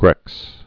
(grĕks)